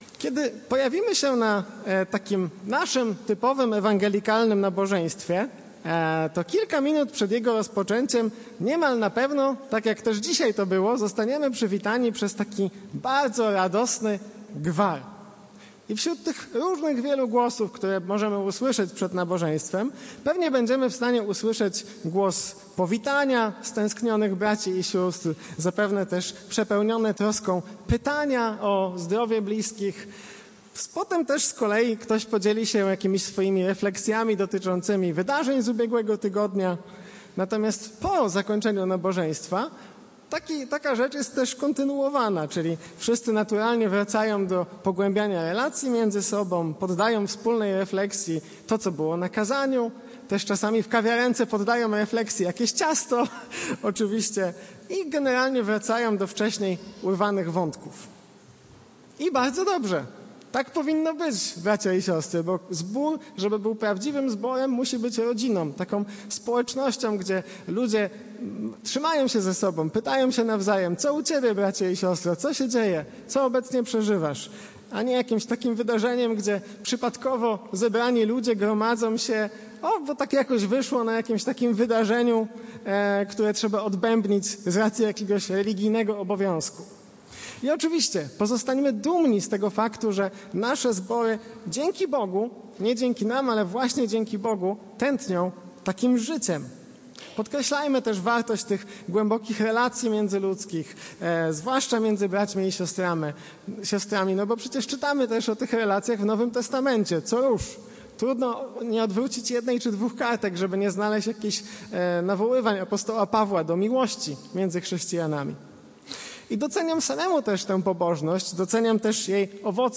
Kazanie
wygłoszone na nabożeństwie